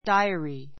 dáiəri ダ イアリ